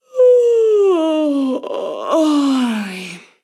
Bostezo de una mujer
Voz humana